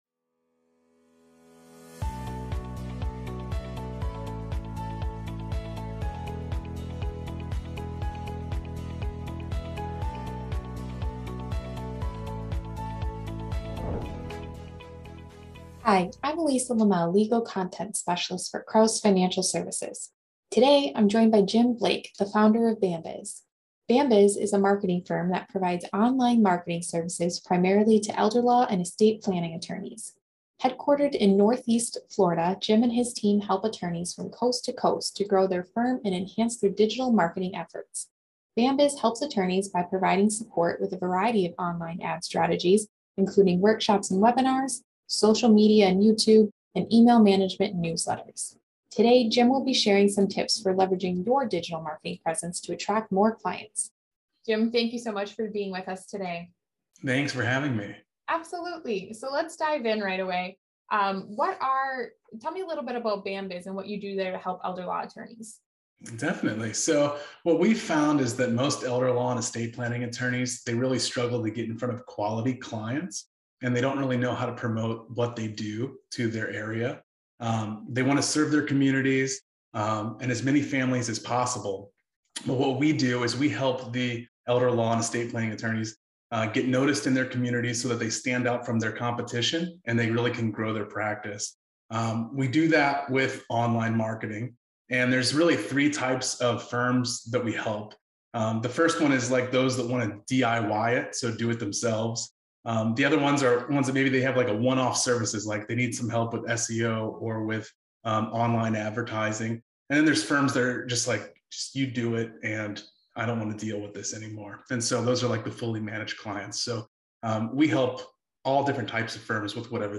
Learn how to enhance your firm’s digital marketing efforts. Watch the interview today!